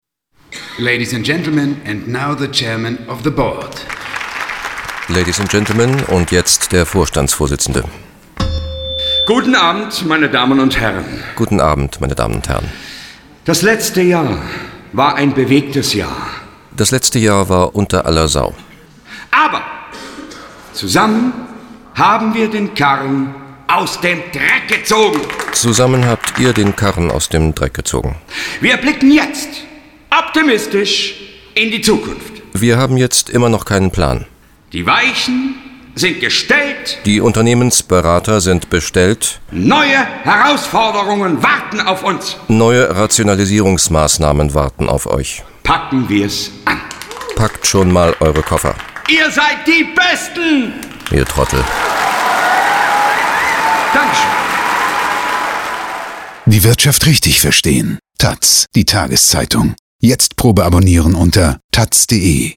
Ausgezeichnet: taz-Werbespot gewinnt “Radiostar”
Ein taz-Radiowerbespot hat in diesem Jahr den “Radiostar” in der Kategorie “Publikumspreis” gewonnen. In dem Spot ist zu hören, wie ein Vorstandsvorsitzender eines Unternehmens eine Ansprache an seine Mitarbeiter hält und dabei die üblichen Floskeln von sich gibt. Seine Ansprache wird synchronübersetzt – aber nicht in eine andere Sprache, sondern in Klartext.